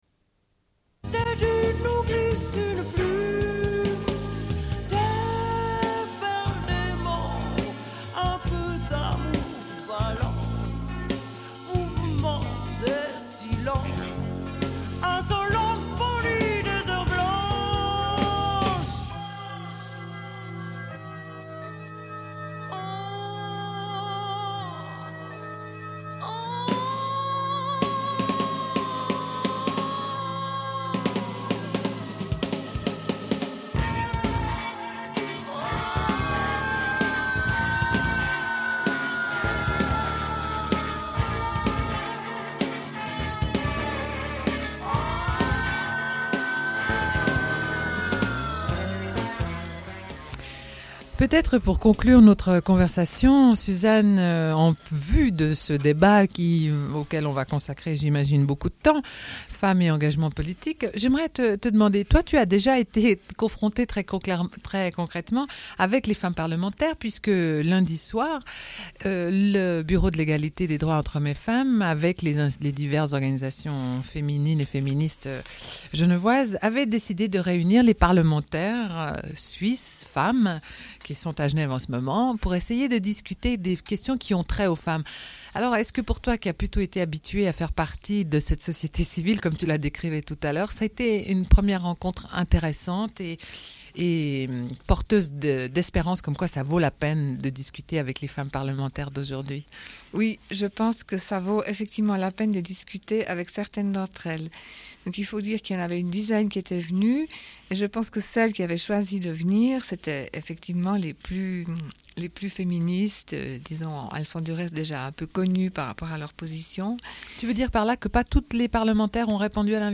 Une cassette audio, face B29:34
La fin de l'émission est le Bulletin d'information de Radio Pleine Lune.